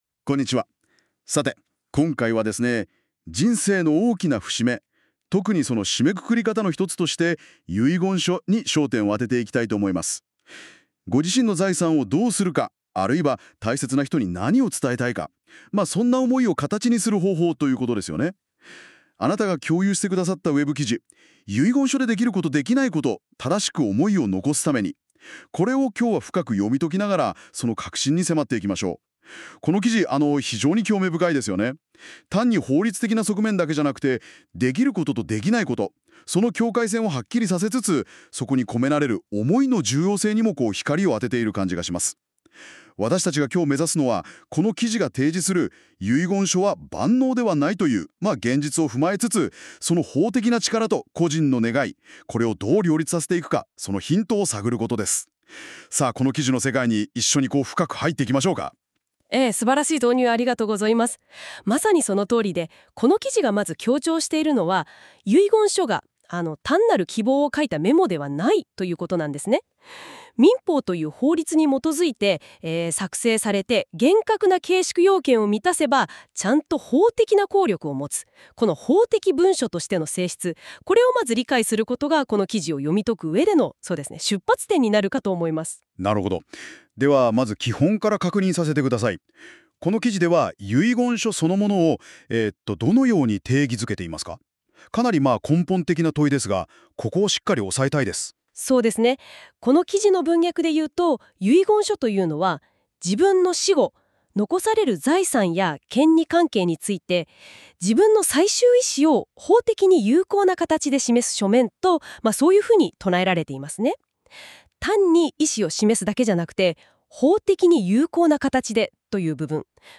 このページの内容をAI要約音声で聞きたい場合は、下の再生ボタンをクリック